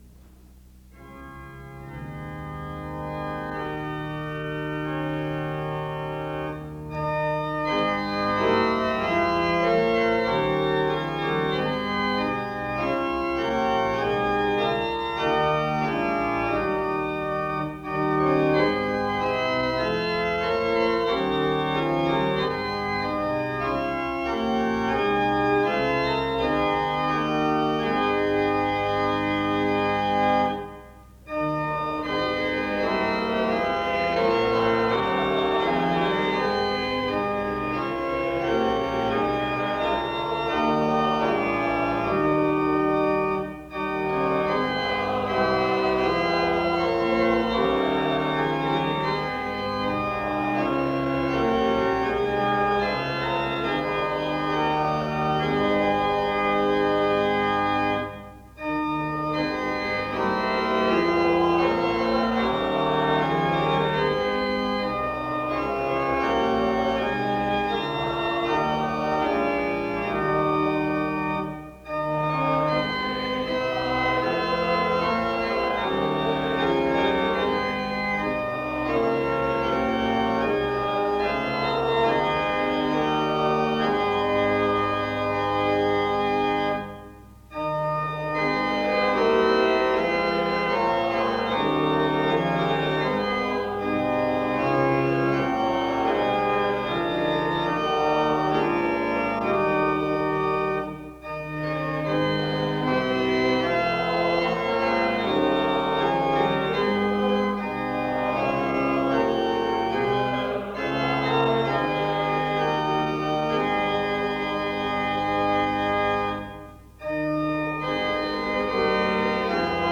Opening music begins the service from 0:00-2:34. Matthew 12:33-37 and Ephesians 4:29 are read from 2:44-3:57. A prayer is offered from 3:58-6:25.
SEBTS Chapel and Special Event Recordings SEBTS Chapel and Special Event Recordings